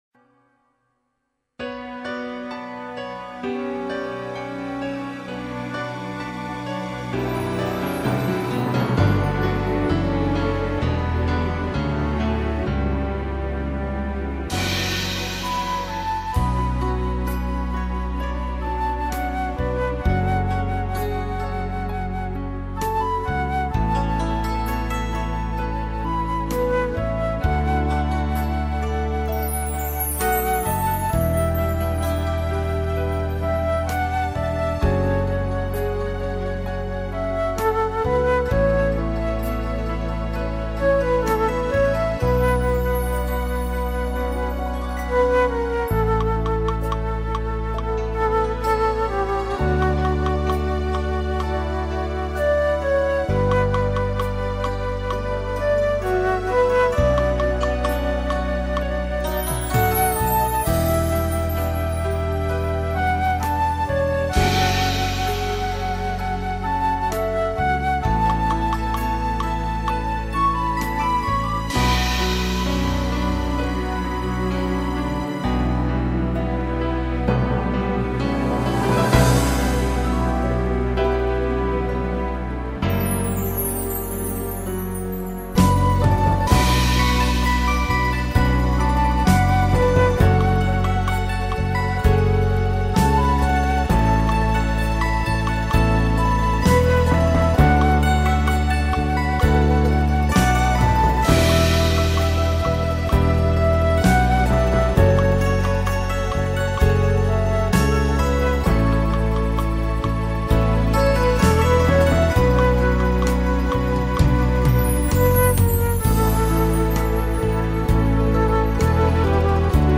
•   Beat  02.
(C#m) (Melody) 3:00